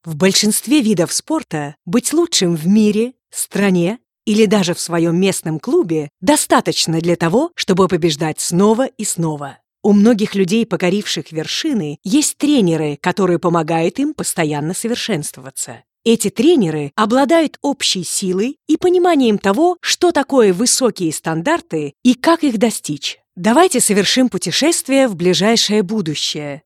warm and deep for narrations, sexy and fun for commercials, smooth and professional for presentations
Sprechprobe: Industrie (Muttersprache):